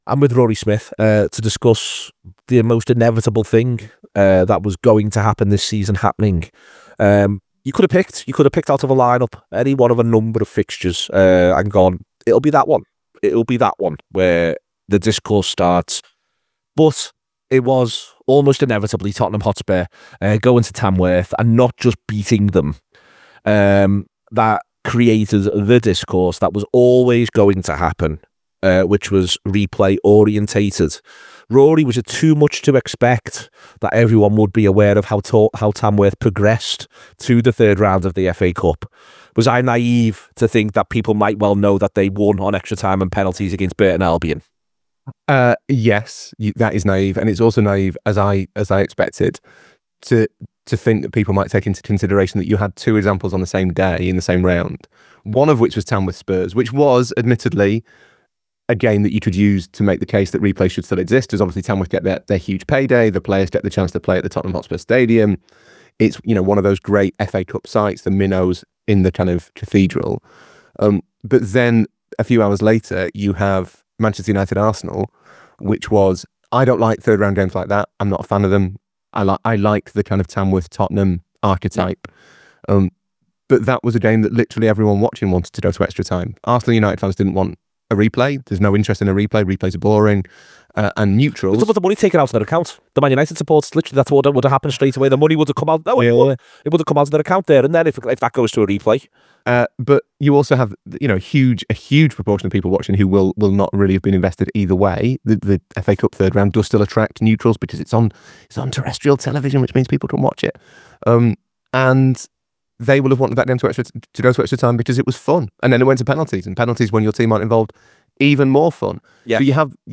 Below is a clip from the show – subscribe for more on the FA Cup format…